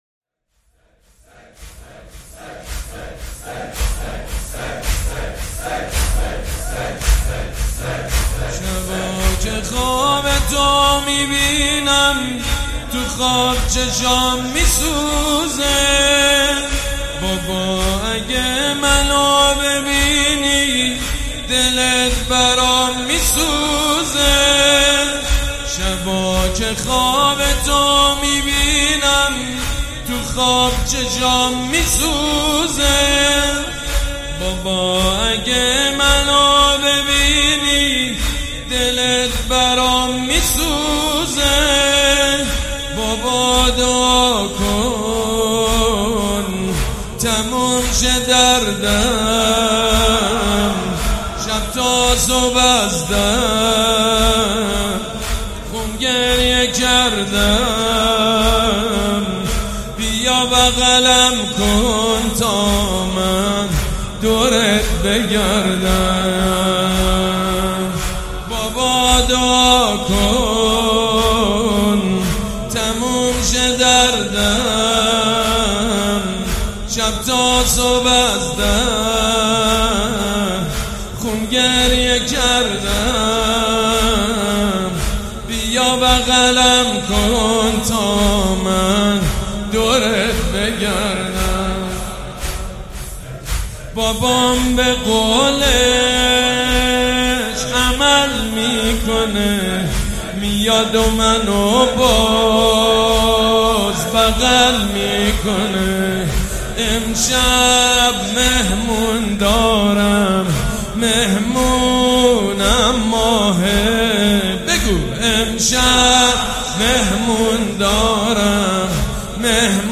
مداحی جدید حاج سید مجید بنی فاطمه حسینیه ی ریحانه الحسین شب سوم محرم97